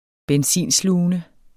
Udtale [ -ˌsluˀənə ]